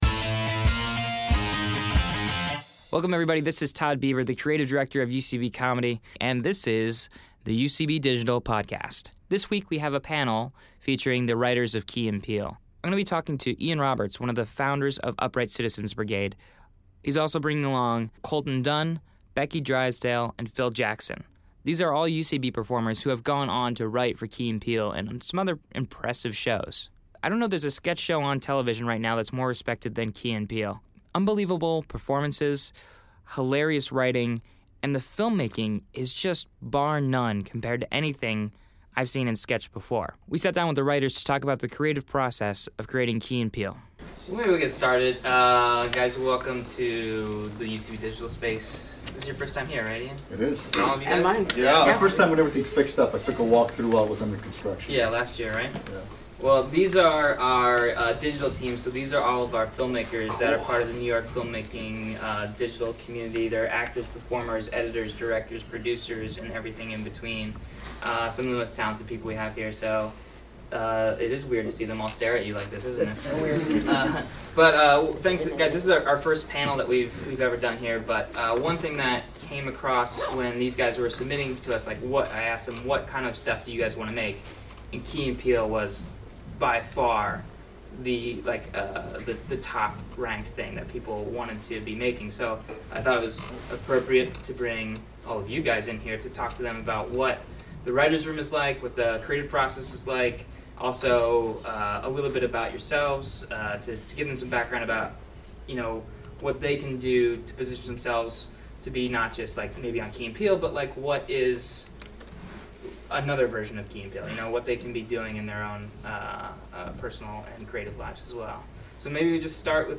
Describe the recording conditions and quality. Recorded live at UCB Comedy studios in NYC during the 16th annual Del Close Marathon.